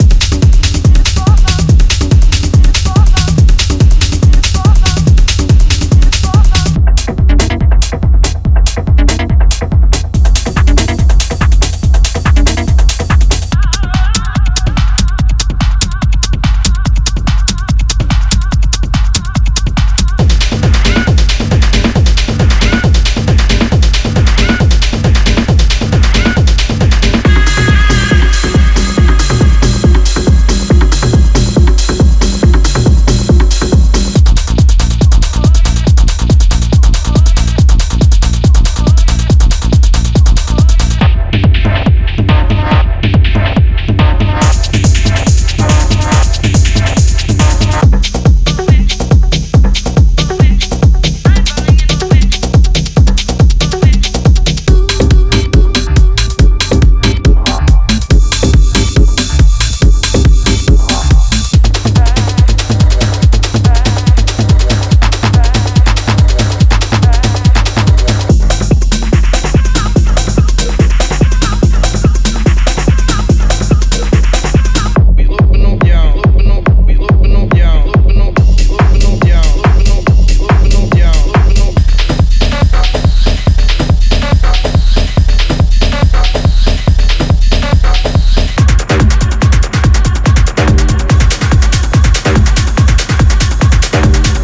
Genre:Techno
デモサウンドはコチラ↓